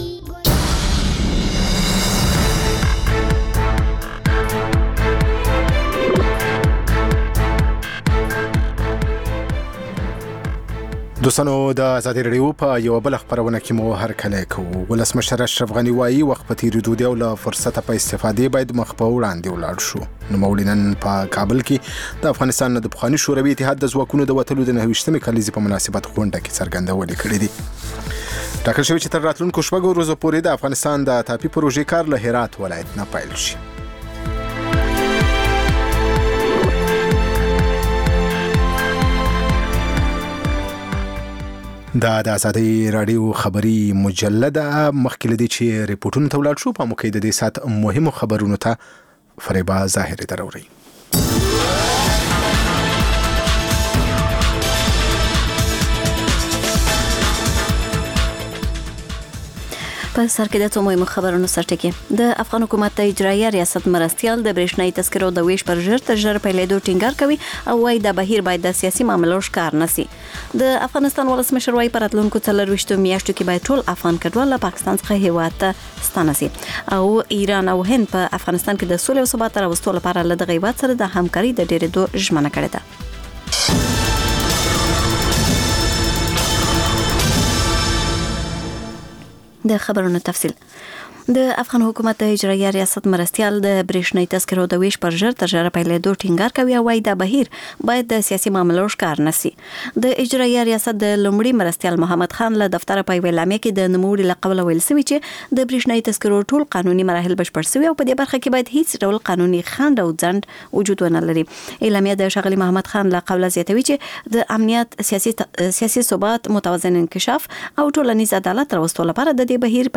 ماښامنۍ خبري مجله